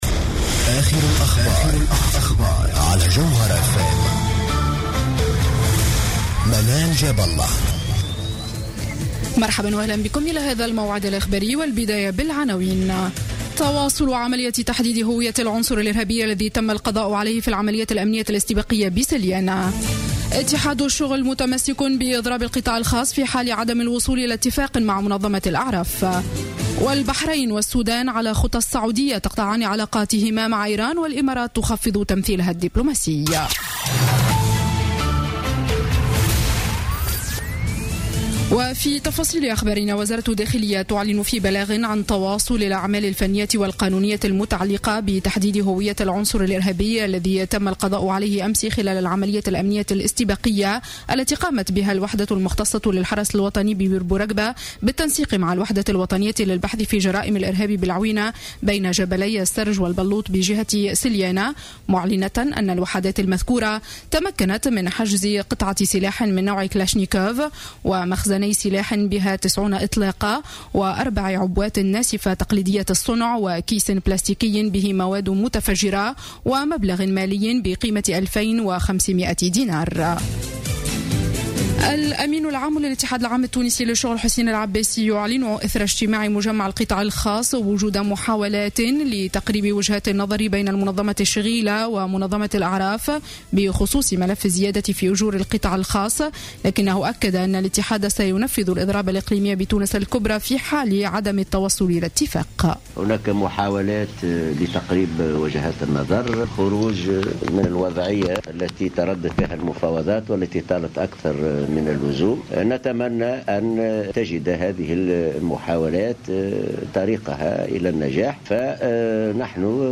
نشرة أخبار السابعة مساء ليوم الاثنين 4 جانفي 2016